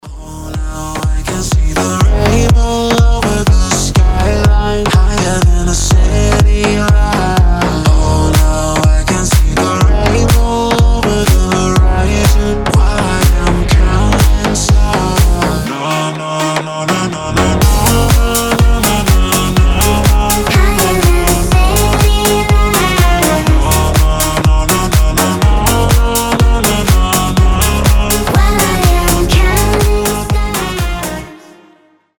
• Качество: 320, Stereo
веселые
детский голос
slap house